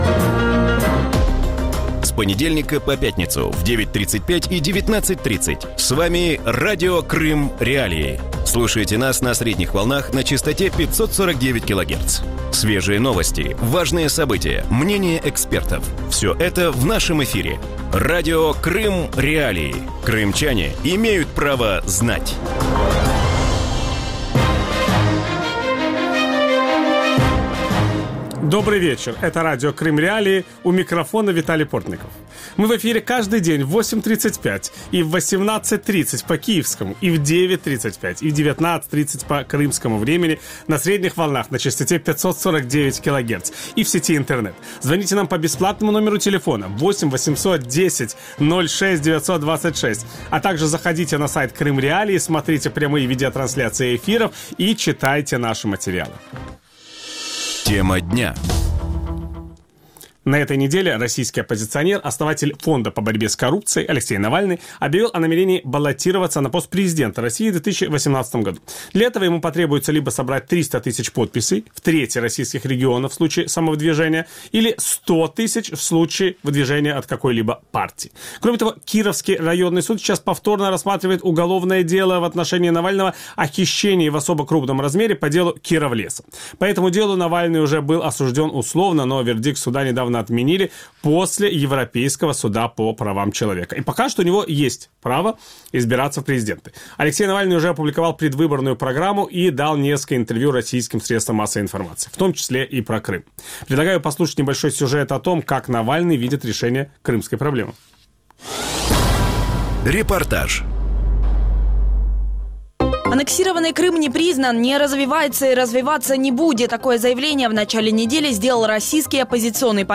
У вечірньому ефірі Радіо Крим.Реалії говорять про ставлення російської опозиції до кримського питання. Чому статус півострова викликає таку неоднозначну реакцію серед російських опозиціонерів і чи зможуть вони прийти до влади у Росії? На ці питання відповість російський політолог Дмитро Орєшкін. Ведучий: Віталій Портников.